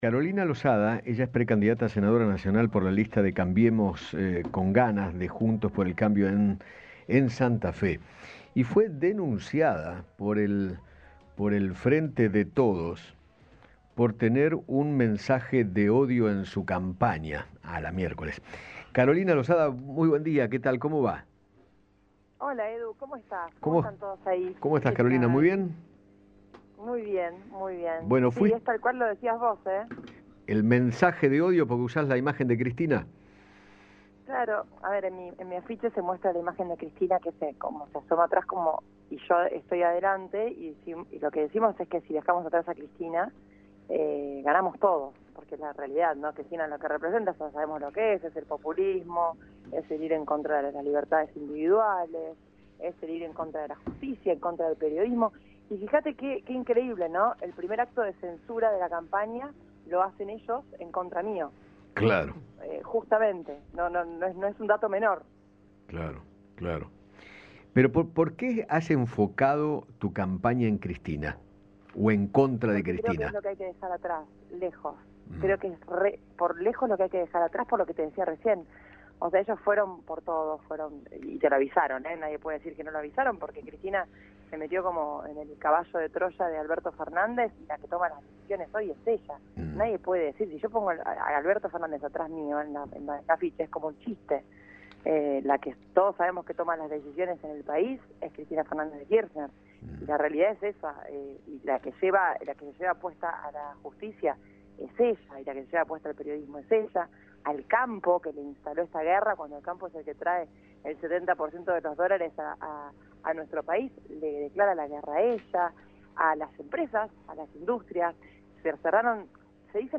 Carolina Losada, precandidata a senadora nacional, dialogó con Eduardo Feinmann sobre la presencia de Cristina Kirchner en el gobierno y aseguró que es a quien “hay que dejar atrás” en las próximas elecciones.